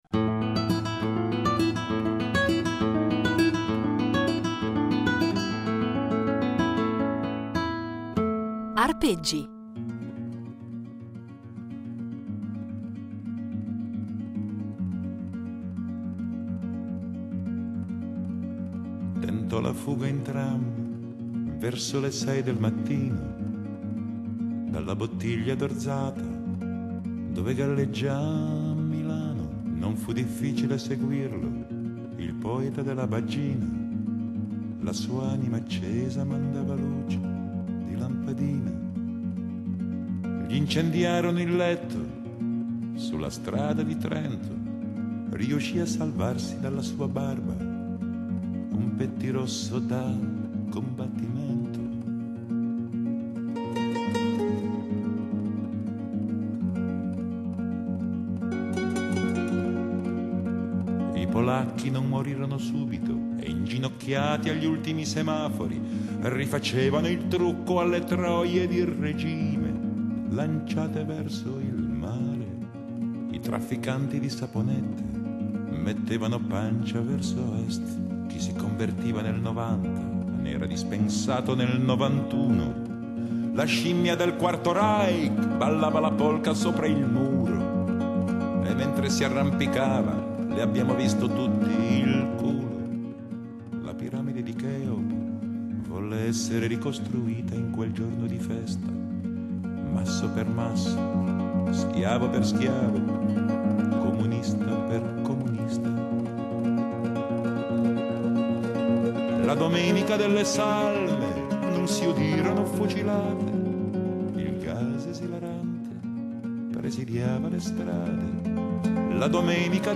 chitarra
cantante
polistrumentista